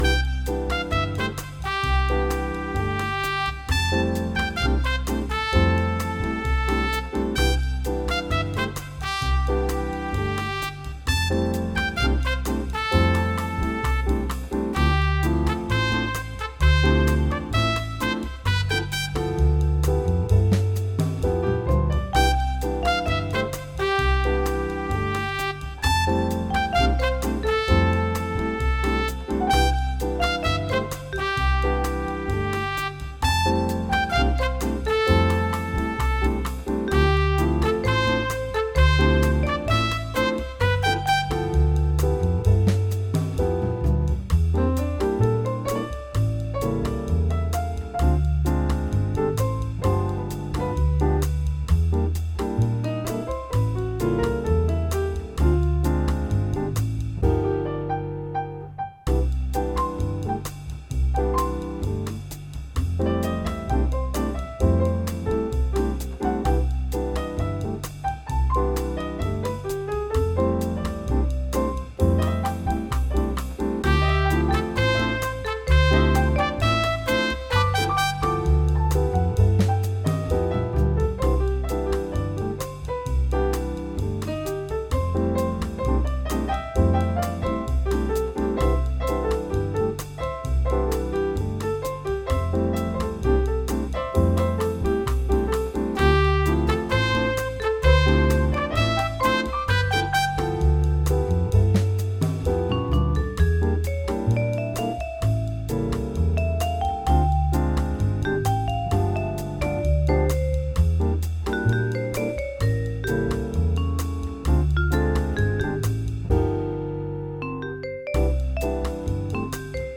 KitchenBossaNeu.mp3